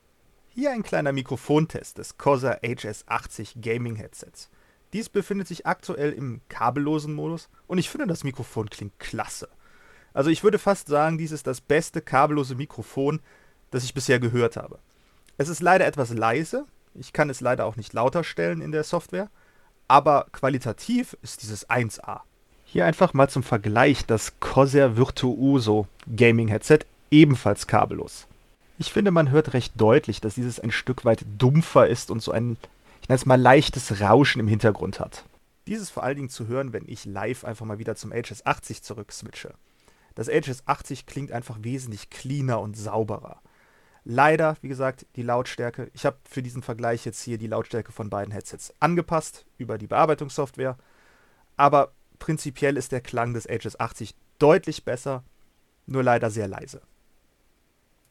Das Mikrofon klingt richtig gut!
Sicherlich sind wir hier nicht auf dem Level eines gesonderten Tischmikrofons oder der “S” Klasse der kabelgebundenen Gaming Headsets, aber mir ist kein kabelloses Gaming Headset bekannt das besser klingt als das HS80.
Leider ist das Mikrofon nur etwas leise, qualitativ kann ich aber wirklich nicht meckern.
Mikrofon-mit-vergleich.mp3